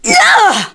Demia-Vox_Damage_kr_02.wav